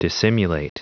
Prononciation du mot dissimulate en anglais (fichier audio)
Prononciation du mot : dissimulate